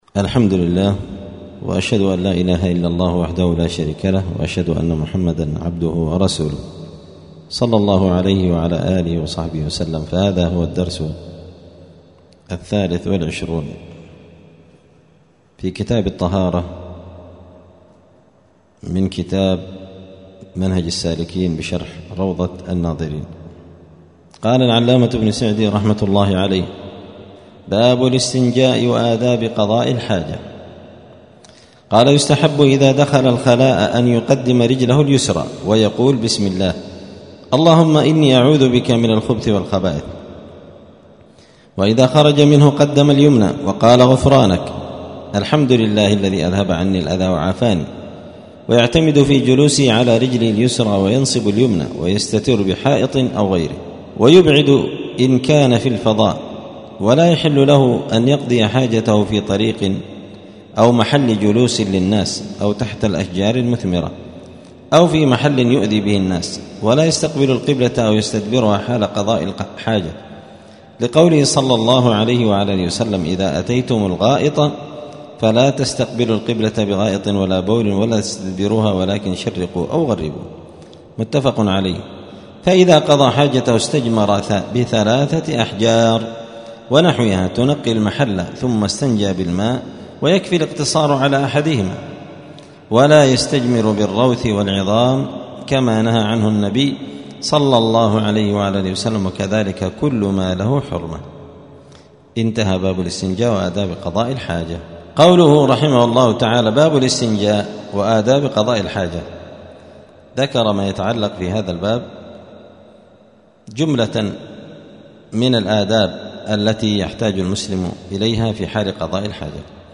*الدرس الثالث والعشرون (23) {كتاب الطهارة باب الاستنجاء وآداب قضاء الحاجة}*
دار الحديث السلفية بمسجد الفرقان قشن المهرة اليمن